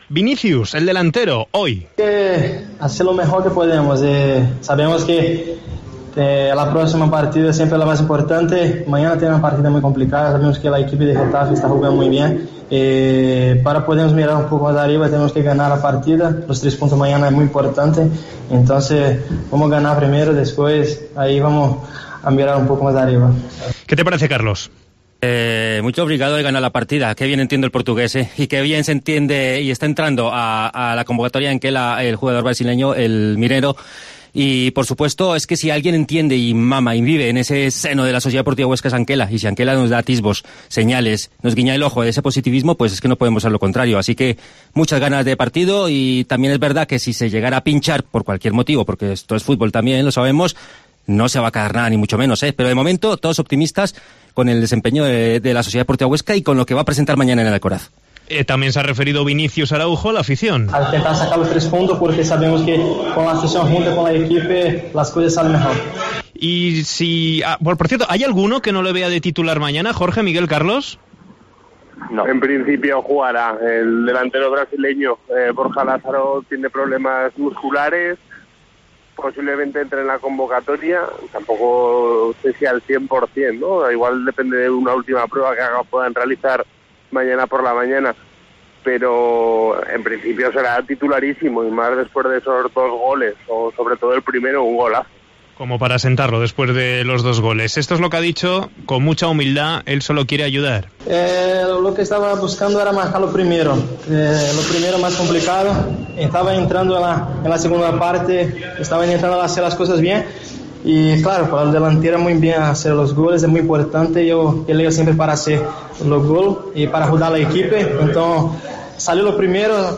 Vinícus en zona mixta